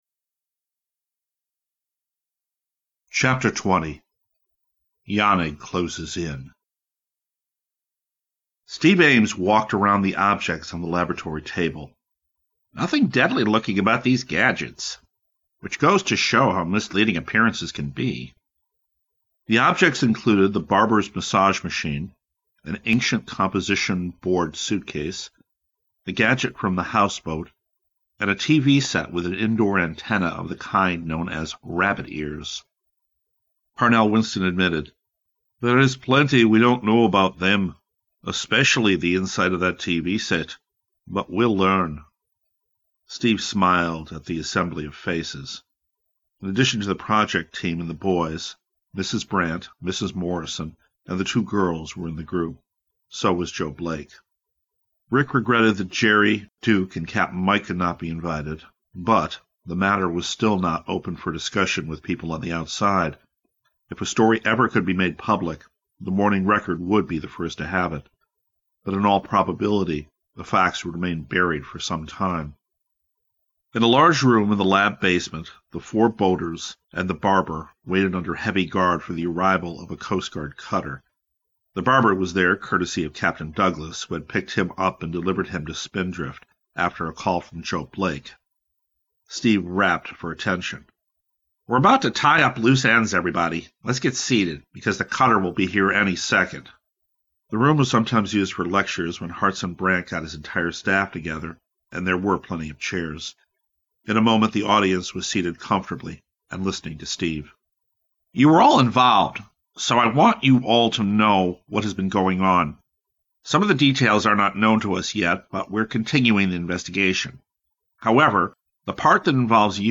This series of podcasts/dramatic readings of kids public domain literature.